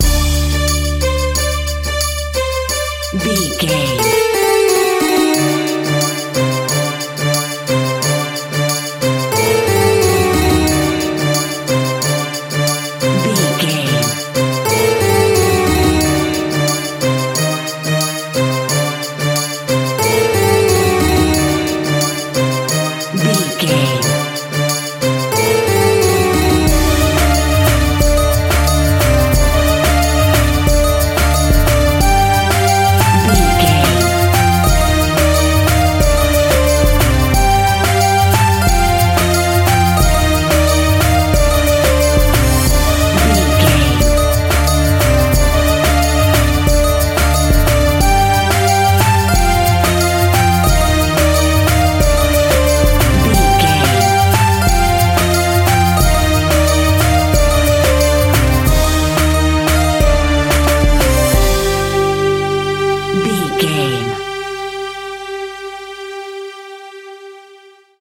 Aeolian/Minor
World Music
percussion